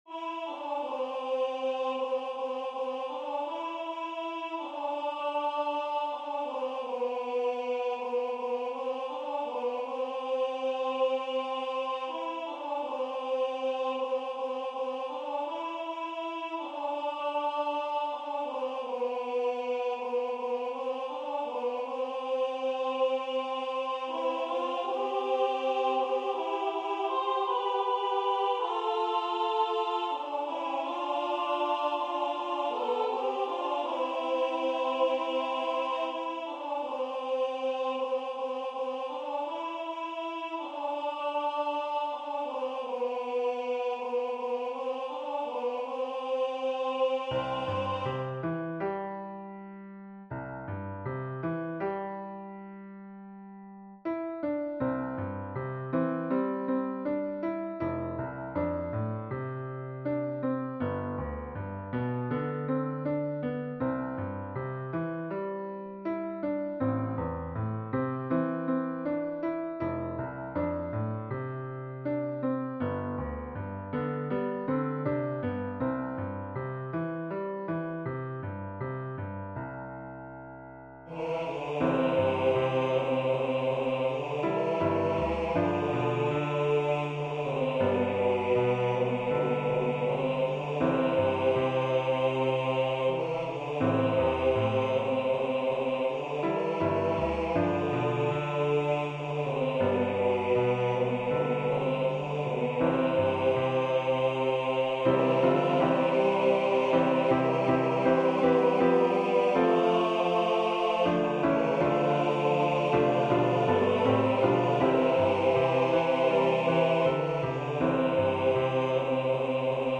Voicing/Instrumentation: SATB We also have other 10 arrangements of " God's Gracious Love ".